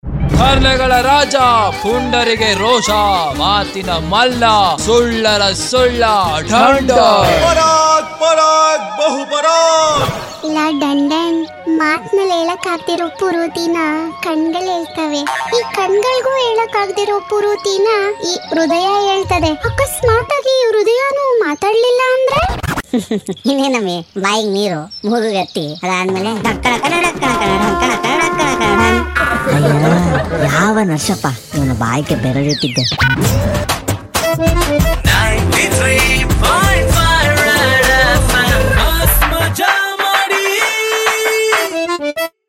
Most Loved Comedy Audio Clip That Makes YOU ROFL!!!